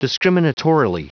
Prononciation du mot discriminatorily en anglais (fichier audio)
Prononciation du mot : discriminatorily